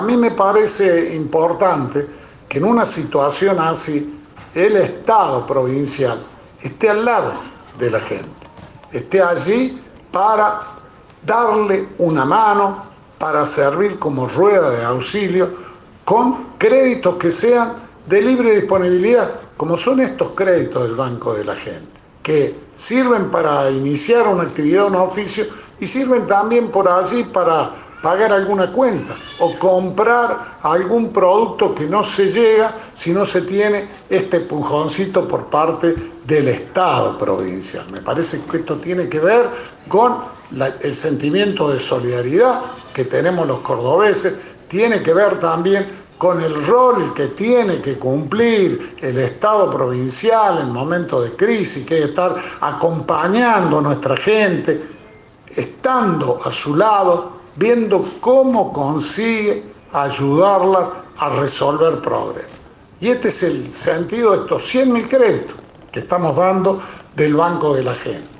El gobernador Juan Schiaretti presidió hoy en el Centro Cívico del Bicentenario la entrega de 500 créditos del Banco de la Gente a familias de la ciudad de Córdoba.
Audio: Juan Schiarett